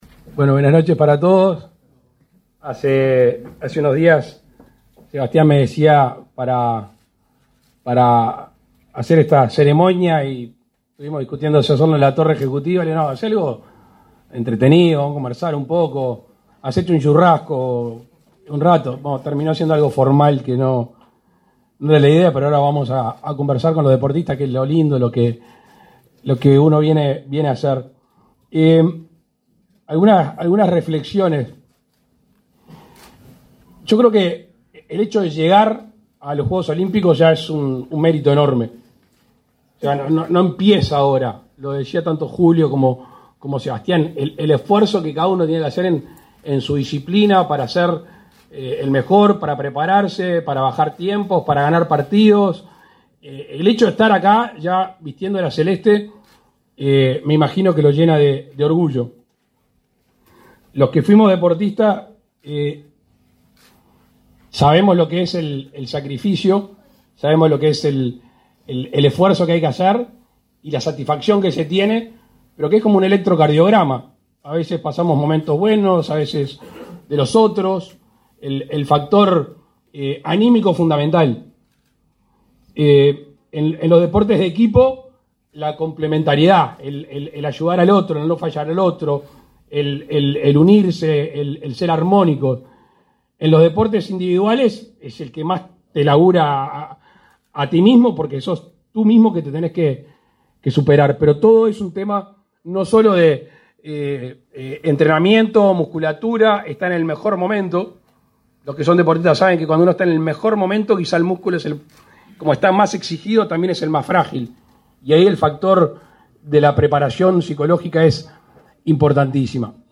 Palabras del presidente de la República, Luis Lacalle Pou
Con la participación del presidente de la República, Luis Lacalle Pou, la Secretaría Nacional del Deporte despidió, este 9 de julio, a la delegación